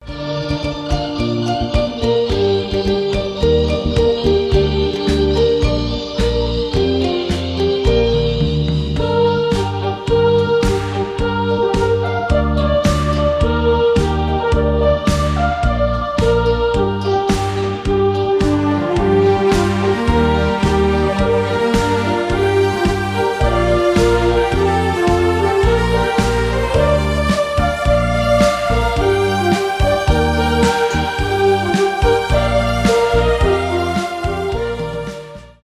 инструментальные , труба , психоделический рок , без слов
альтернатива rock